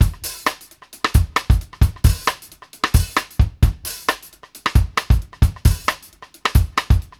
X-STICK FU-L.wav